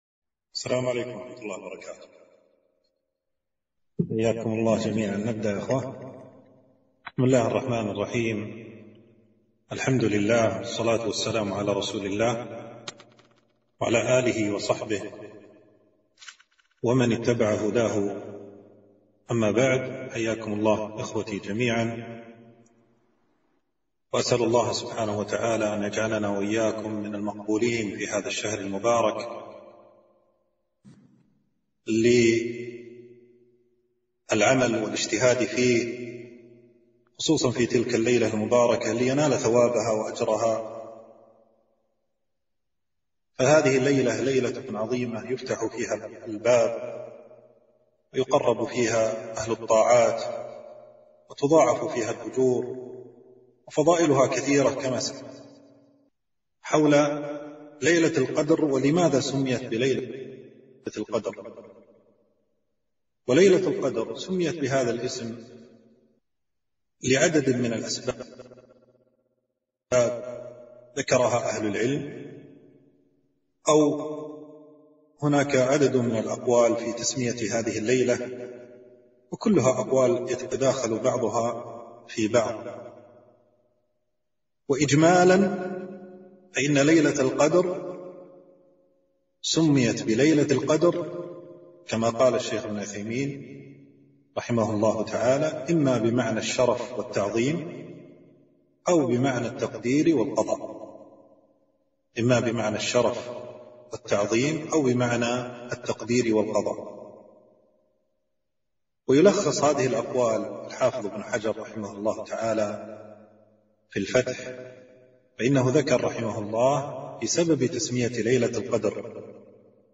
محاضرة - فضائل ليلة القدر وأحكامها